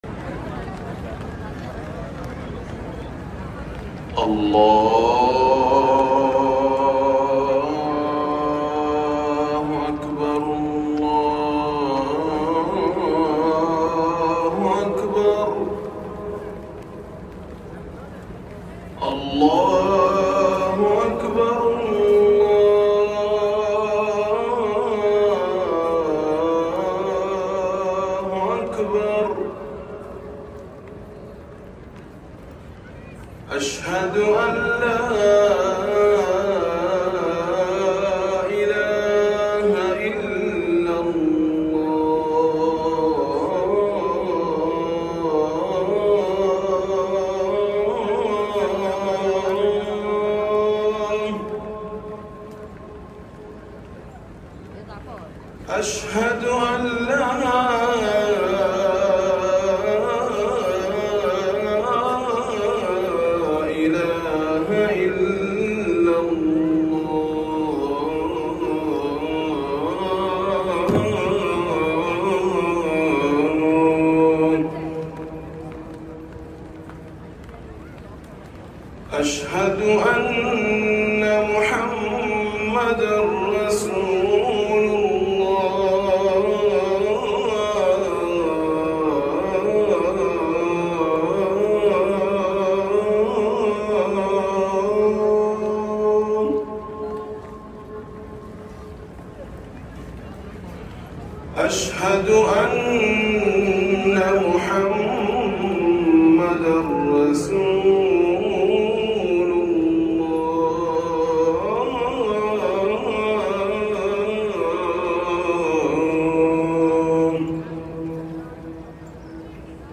الأذان الأول لصلاة الفجر